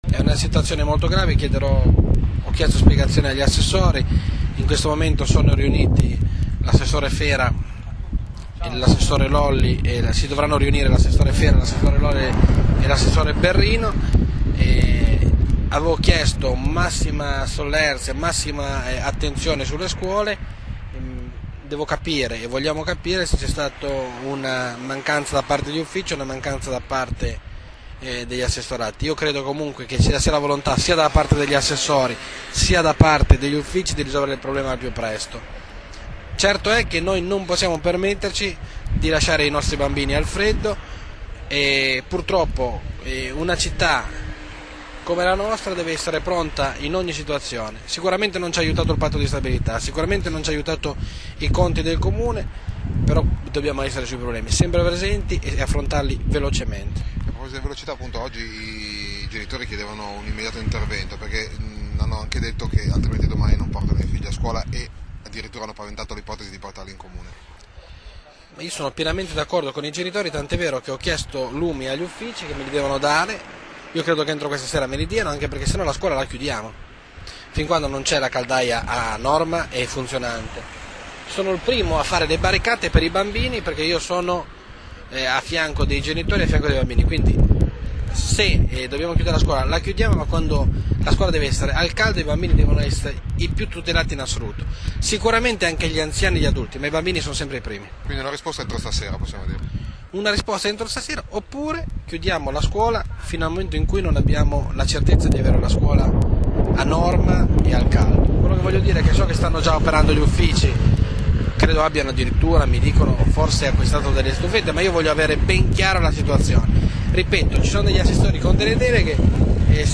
“E’ una situazione molto grave – ha detto il Sindaco (l’audio dell’intervista cliccando